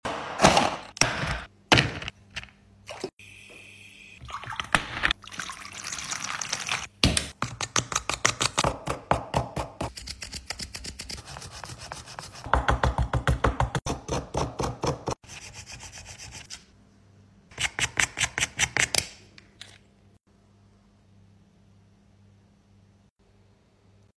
ASMR Underwater Minecraft Mine Kit sound effects free download
ASMR Underwater Minecraft Mine Kit Mining!